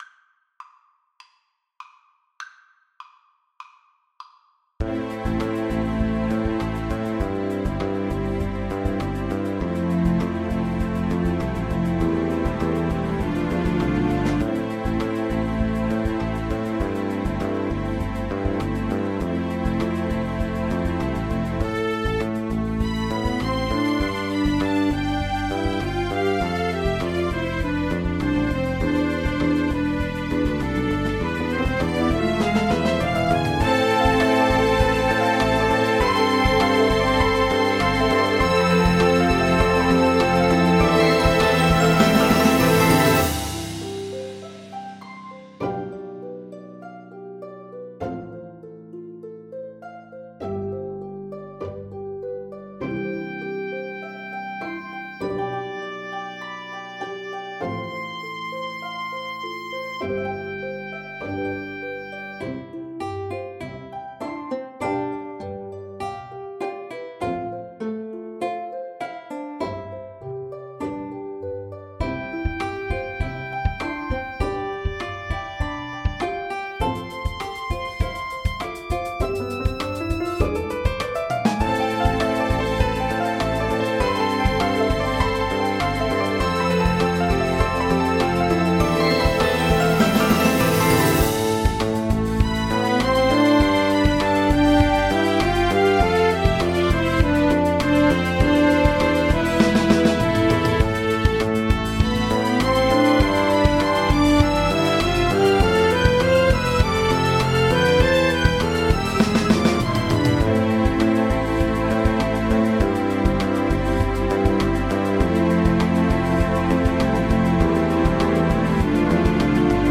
No parts available for this pieces as it is for solo piano.
4/4 (View more 4/4 Music)
Mournfully but with energy = 100
Piano  (View more Intermediate Piano Music)
Classical (View more Classical Piano Music)